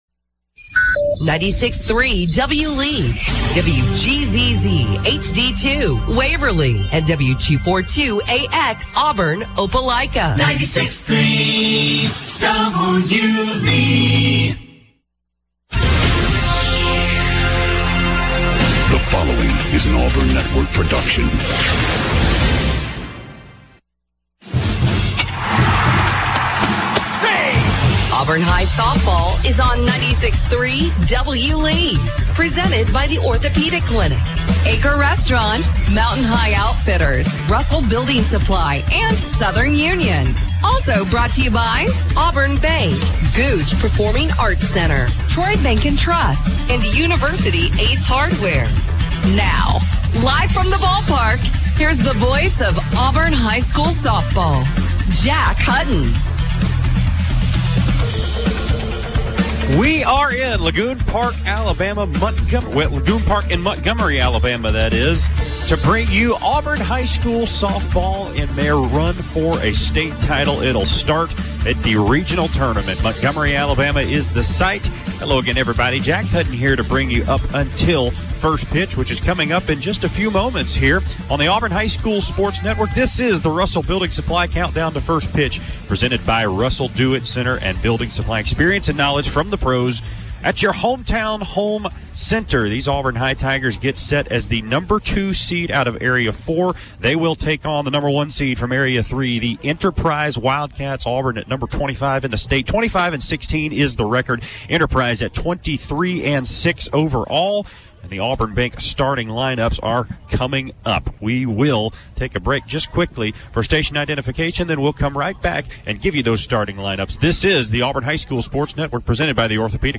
as he calls Auburn High versus Enterprise in Game 1 of the Regional Tournament. The Tigers lost 3-0.